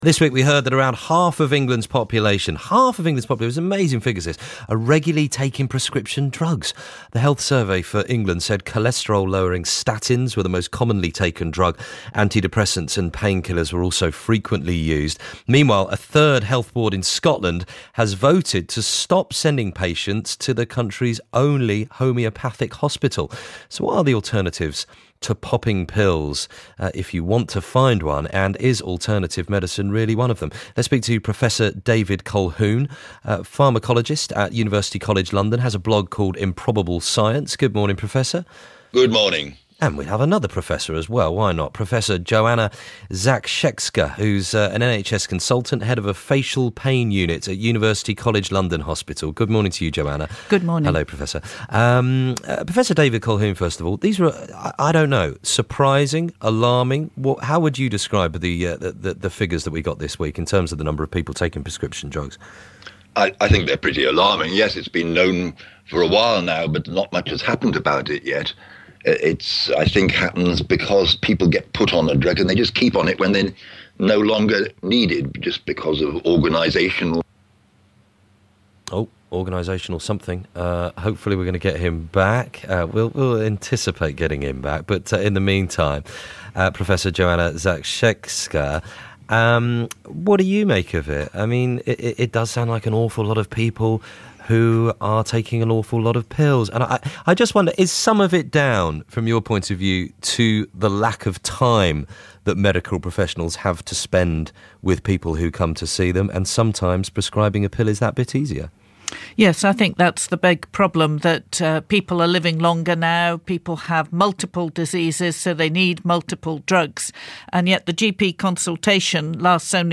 Interview on Radio 5 live about over-treatment and over-diagnosis. It was far too short (and not helped by my accidentally hitting mute button on headset).